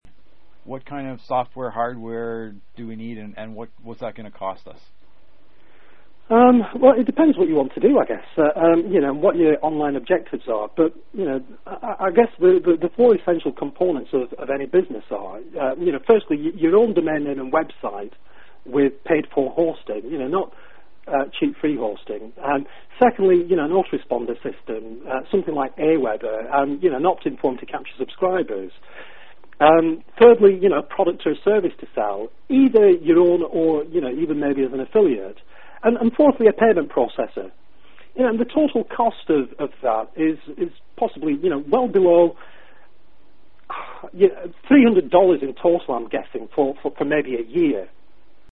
Complete MP3 Audiobook in 3 parts Total 2 hour 31 minutes